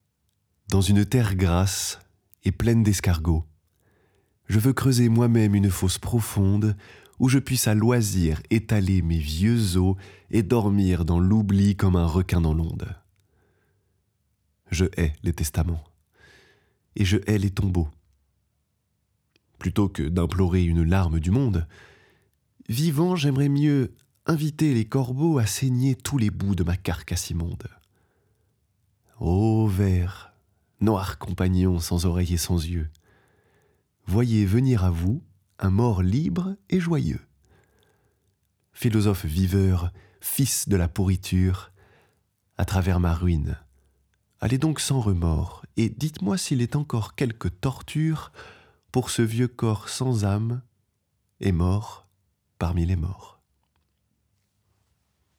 22 - 45 ans - Baryton